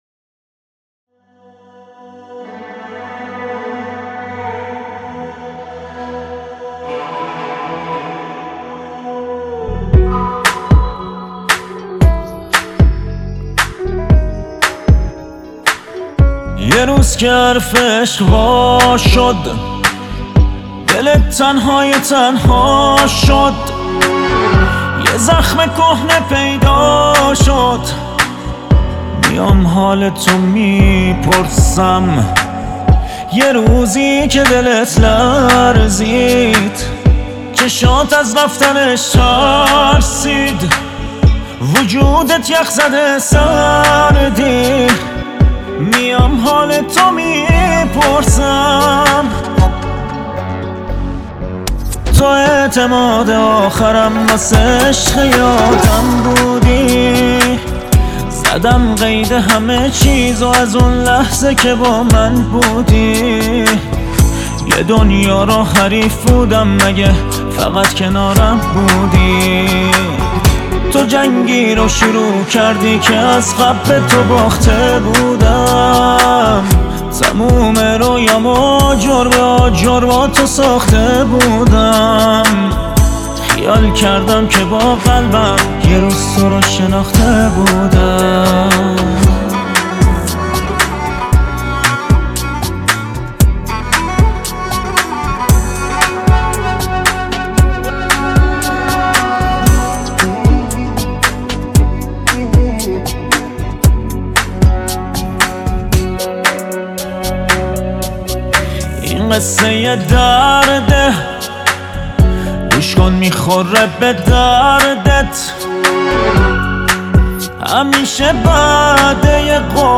آهنگی که هم ملودی‌اش دل‌نشینه، هم شعرش پر از حس و معناست.
• 🎶 سبک: پاپ احساسی